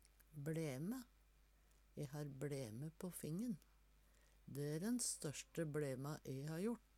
bLæme - Numedalsmål (en-US)